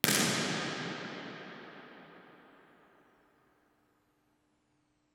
The Lobby:  The lobby is a massive space of 16,686 sq ft (1,550.2 m2). The very low absorption results in a T30 that approaches 6 sec in the 2 kHz octave band.
Microphone: Sennheiser Ambeo
Source: 14 sec log sweep
Lobby Example (Omni):